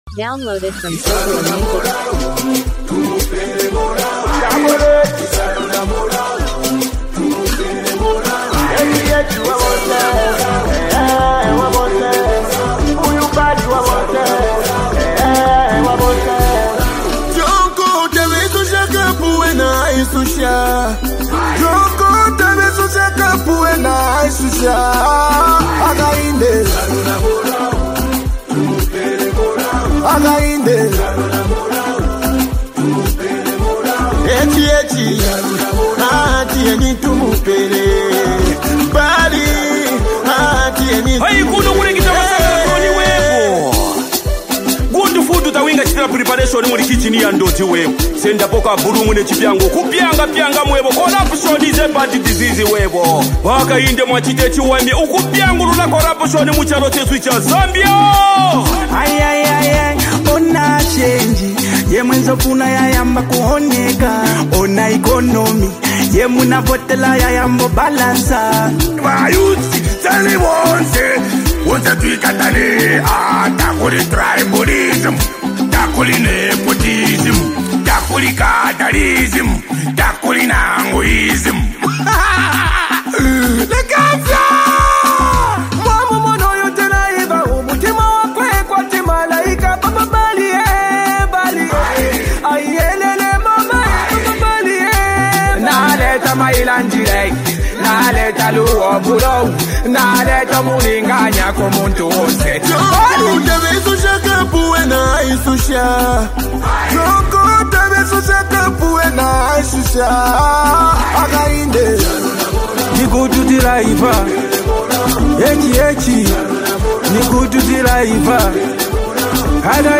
high-energy Zambian political campaign anthem